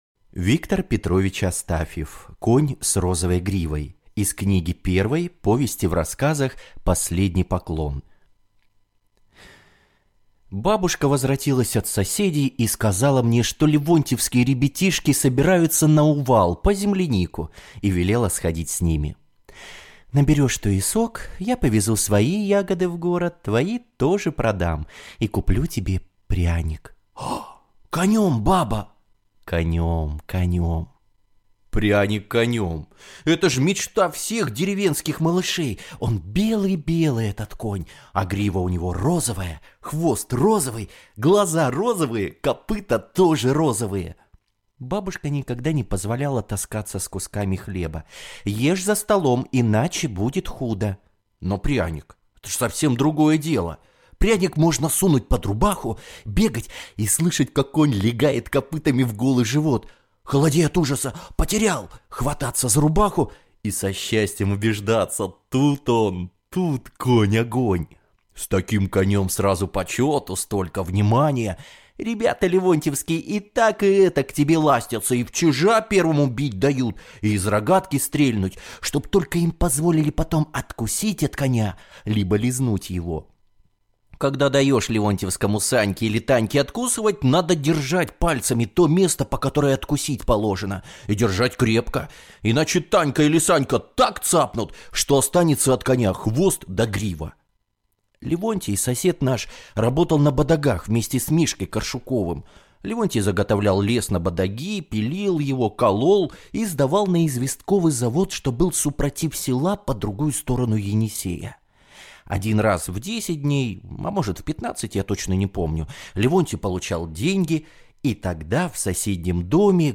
Конь с розовой гривой - Астафьев - слушать рассказ онлайн